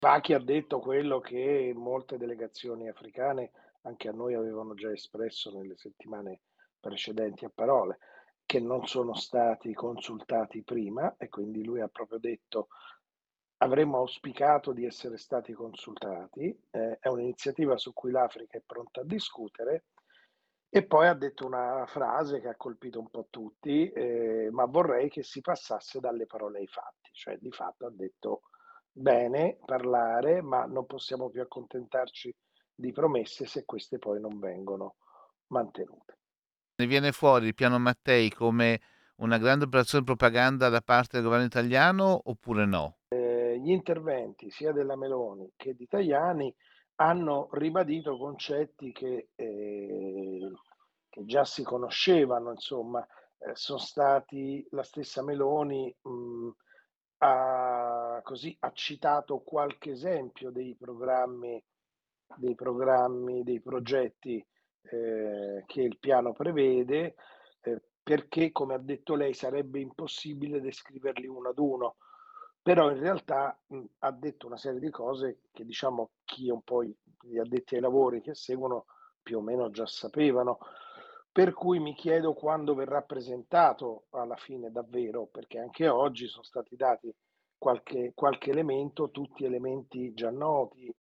giornalista esperto del continente africano